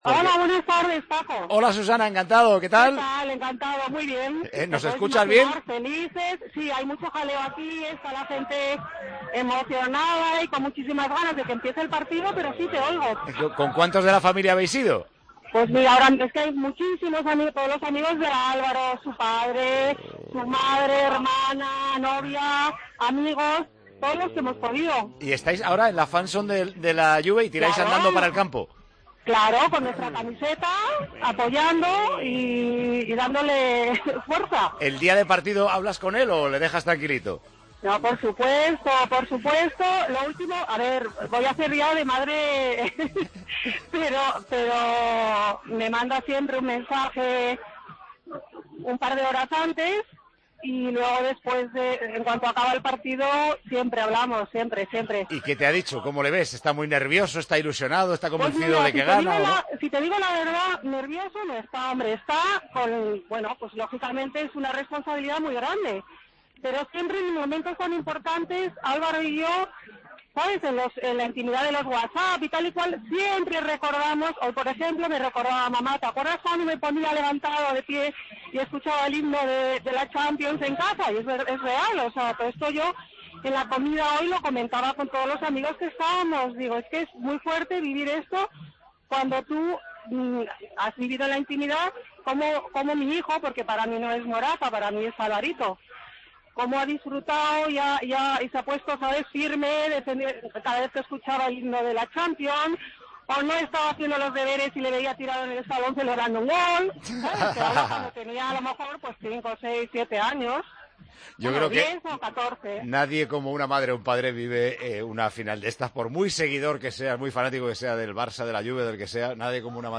desde la fan zone de la Juventus en Berlín.